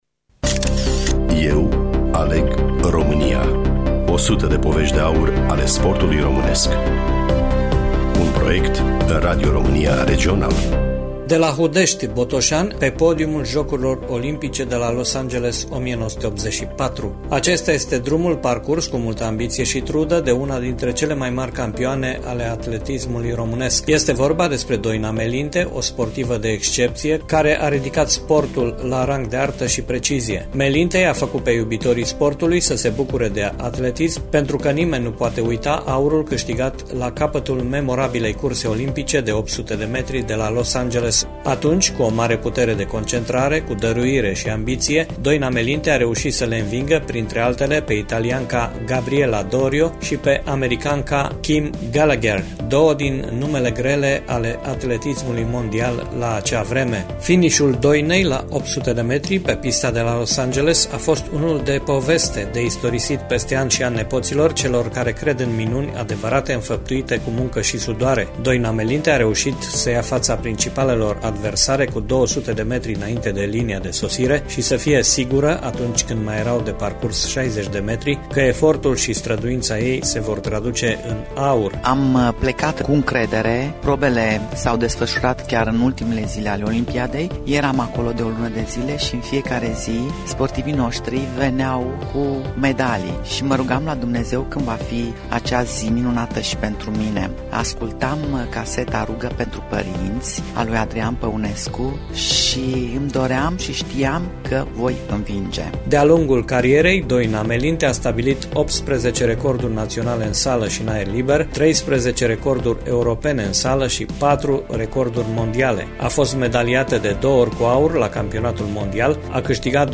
Studioul: Radio România Tg.-Mureş